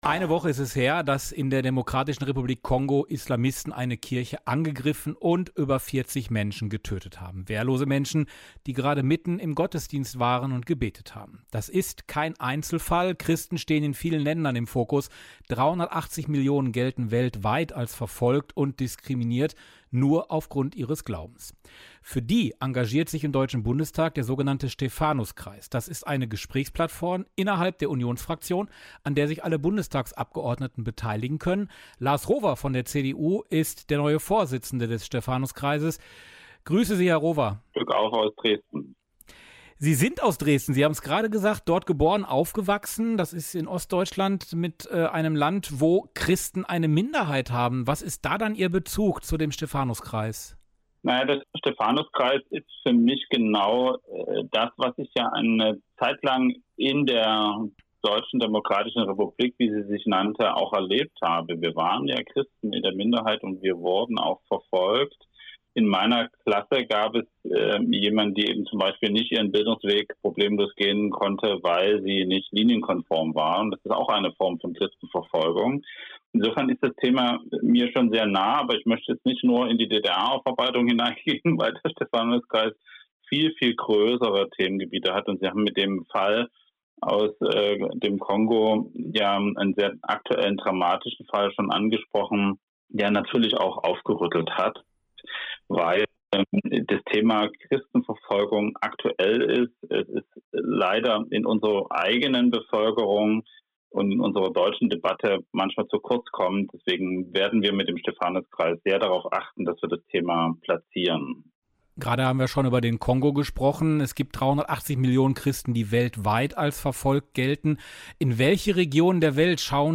Ein Interview mit Lars Rohwer (CDU-Politiker, Mitglied im Bundestag und neuer Vorsitzender des Stephanuskreises)